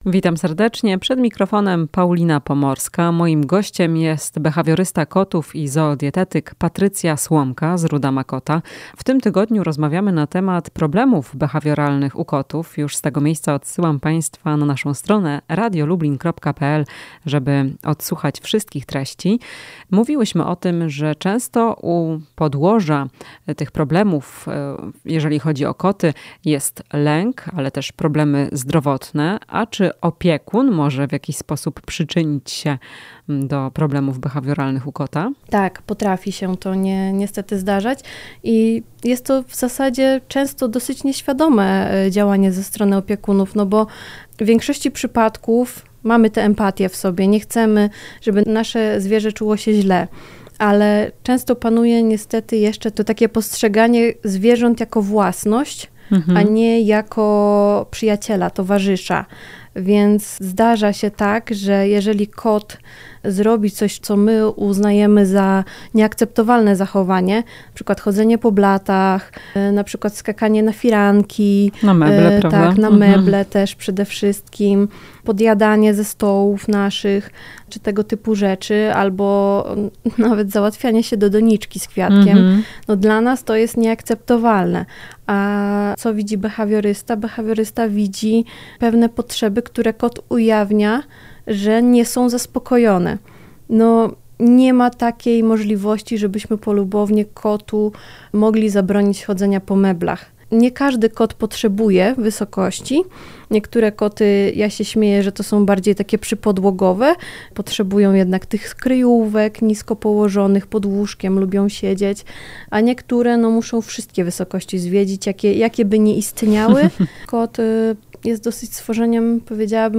W "Chwili dla pupila" powiemy, czy opiekun może przyczynić się do problemów behawioralnych u kota. Rozmowa z behawiorystą kotów, zoodietykiem